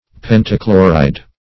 Search Result for " pentachloride" : The Collaborative International Dictionary of English v.0.48: Pentachloride \Pen`ta*chlo"ride\, n. [Penta- + chloride.]